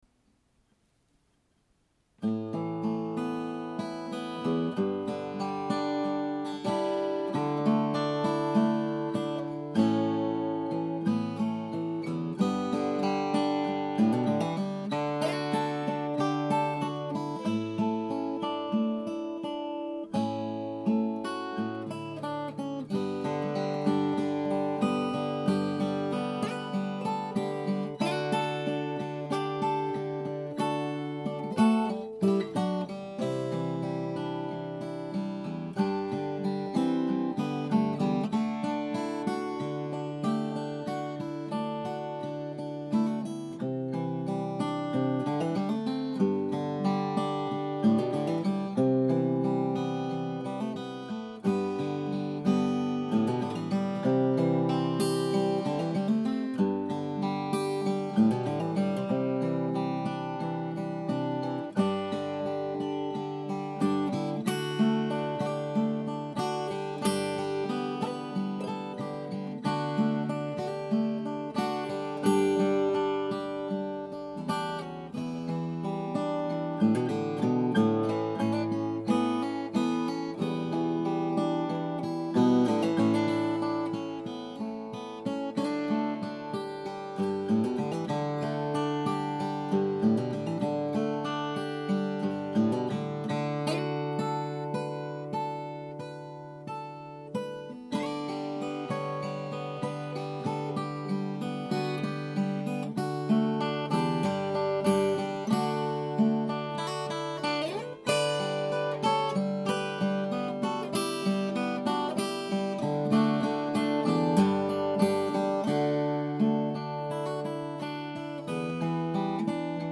Canadian Rockies Suite Guitar Music
This is a collection of six original short guitar pieces.
However, in September of 2010 I began playing again, mainly to record the suite.